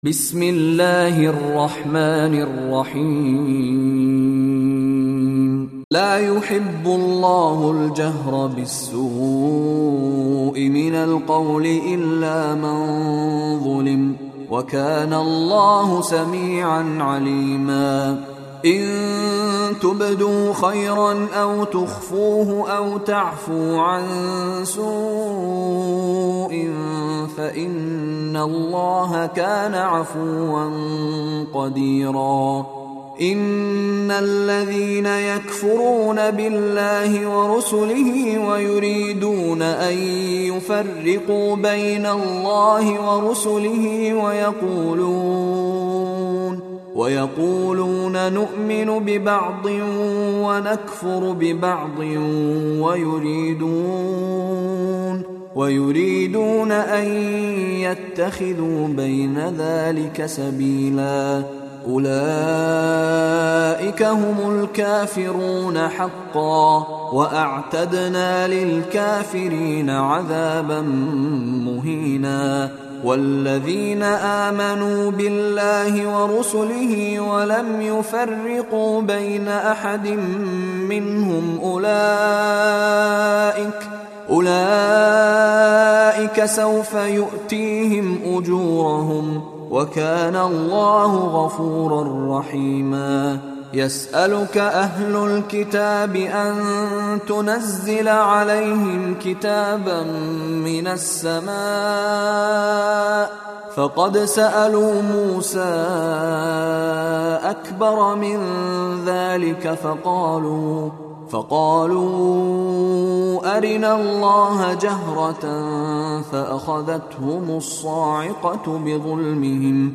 دانلود ترتیل جزء ششم قرآن کریم با صدای مشاری بن راشد العفاسی | مدت : 56 دقیقه